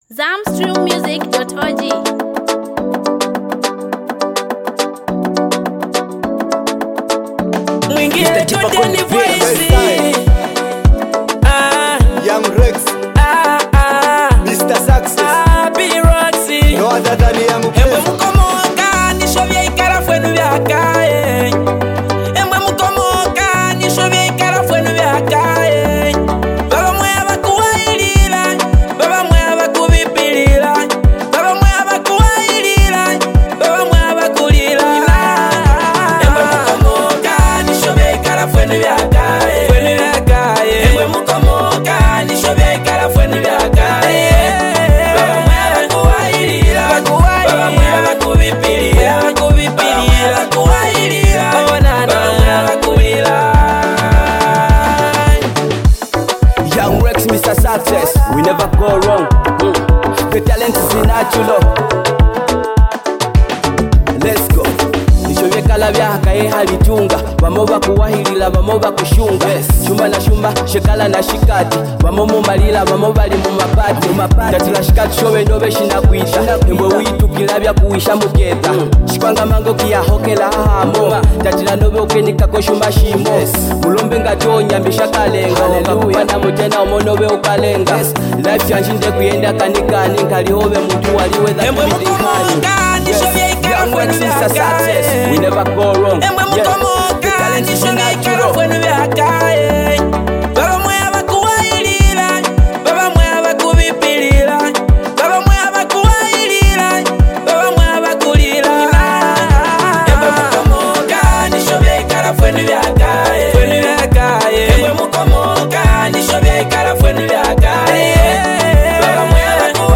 In the vibrant heartbeat of Zambian local music